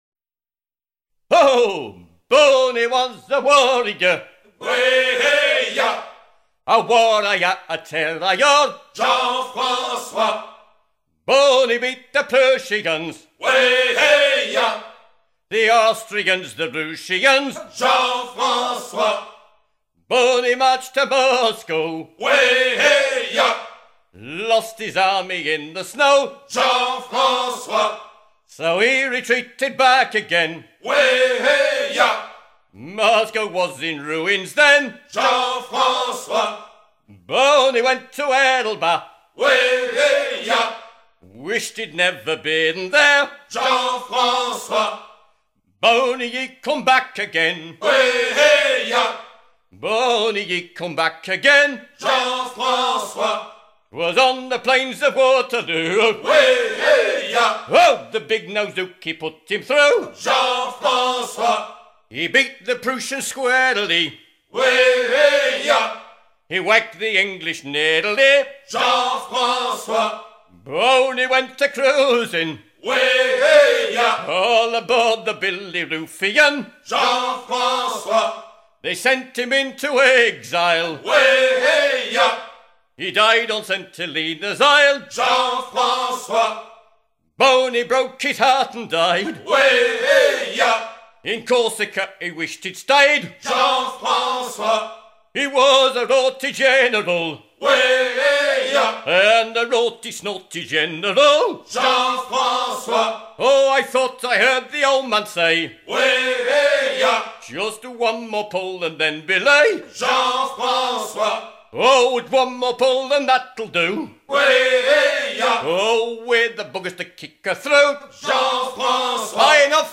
gestuel : à hisser main sur main
Pièce musicale éditée